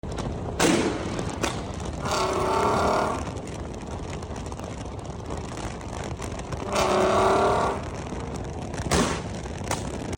R32 Exhaust Sound And Flames Sound Effects Free Download